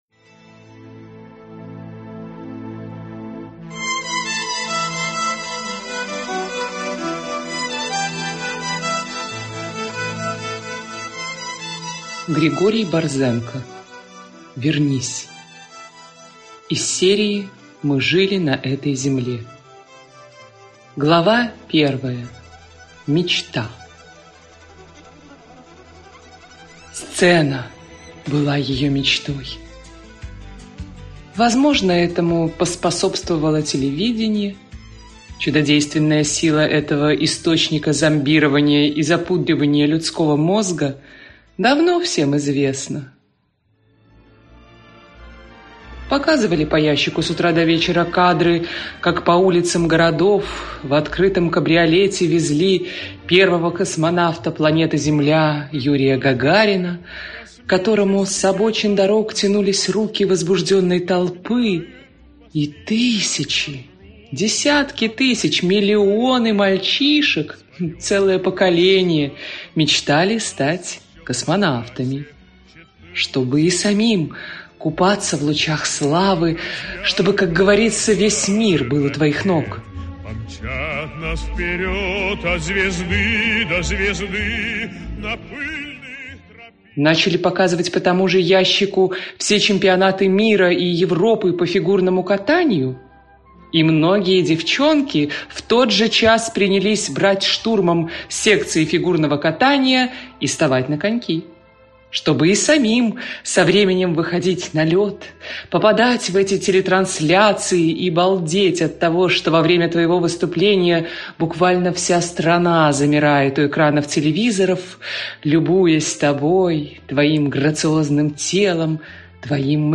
Аудиокнига Вернись | Библиотека аудиокниг
Прослушать и бесплатно скачать фрагмент аудиокниги